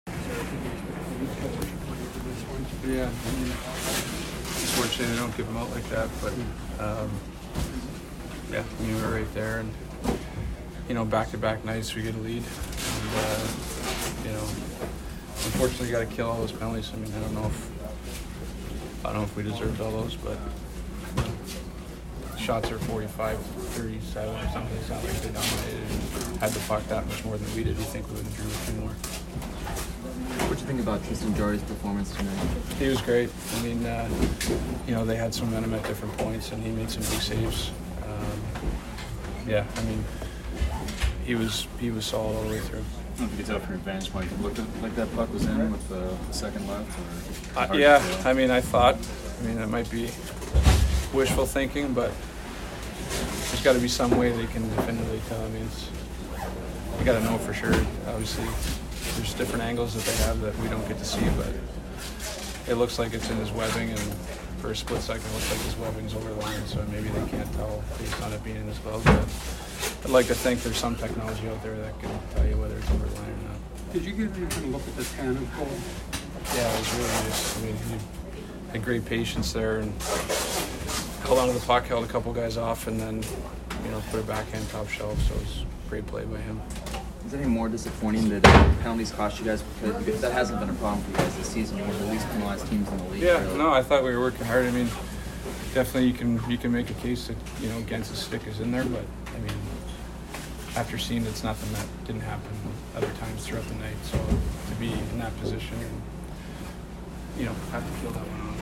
Sidney Crosby post-game 10/23